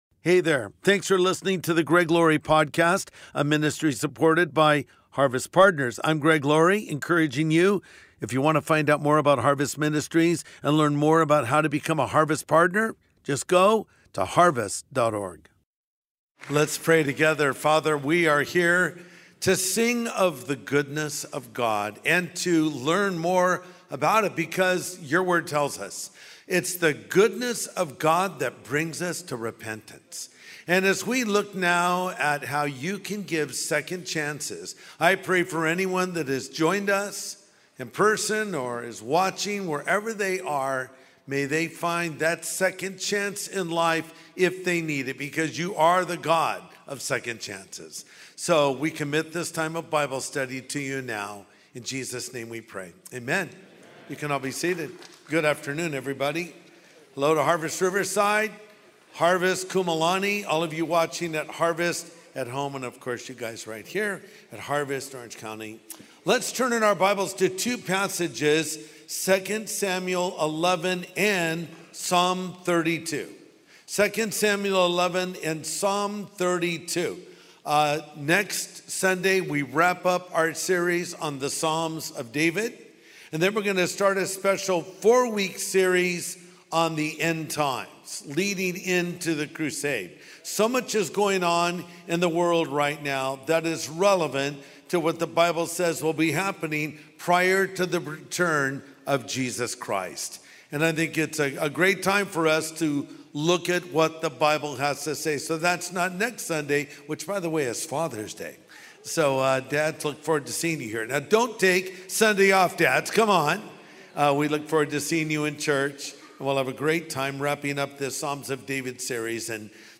The God of Second Chances | Sunday Message